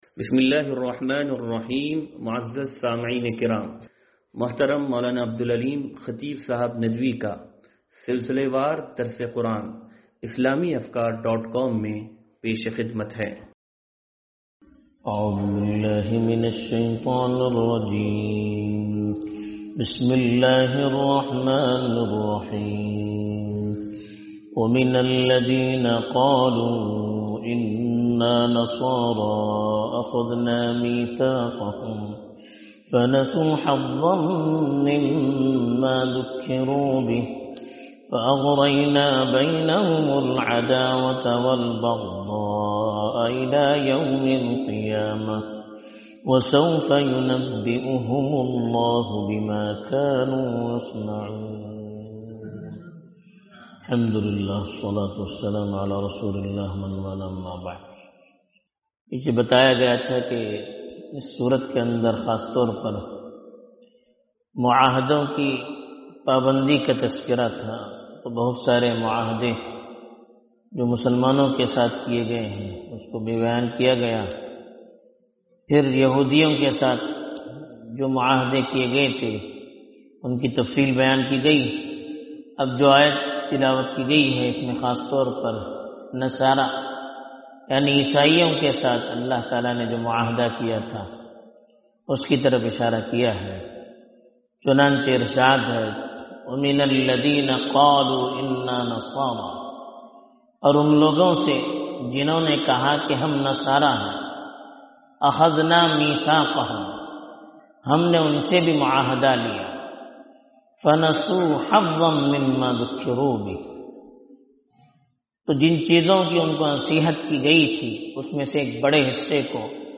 درس قرآن نمبر 0440